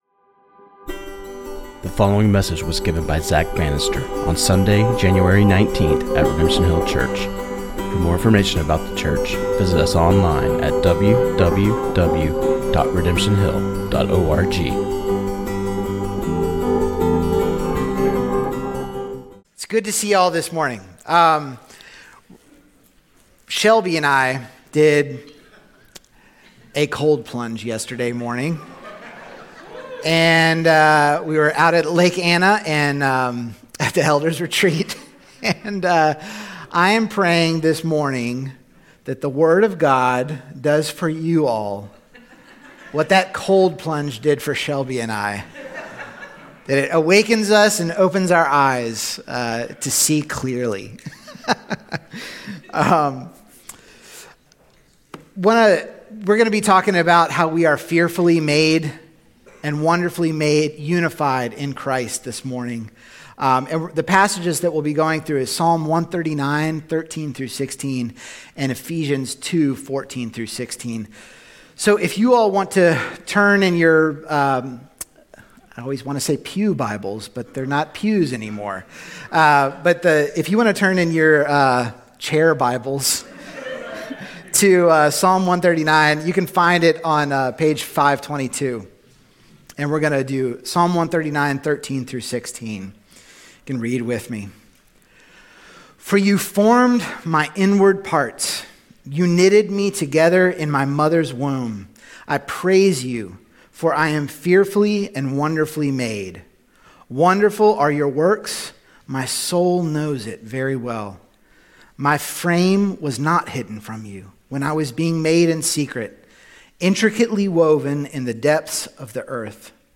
This sermon on Ephesians 2:14-16 and Psalm 139:13-16